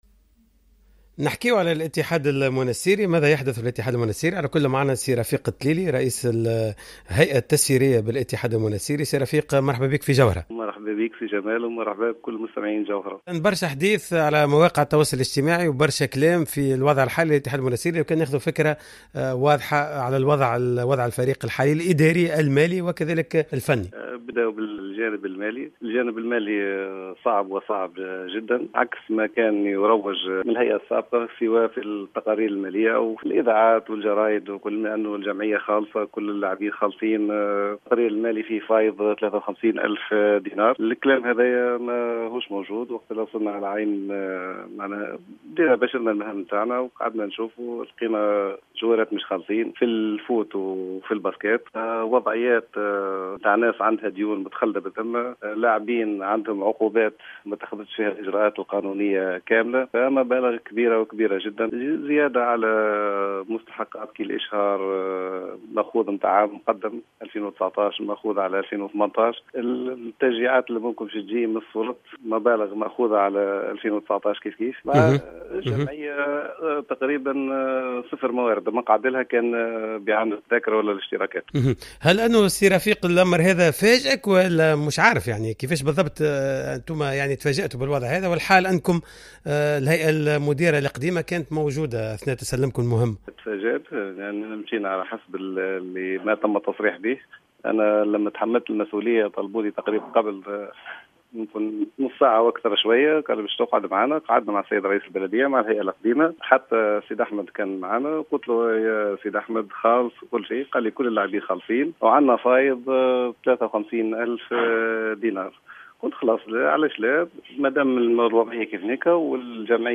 في تدخل مع اذاعة جوهرة فم